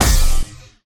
poly_explosion_energynuke.wav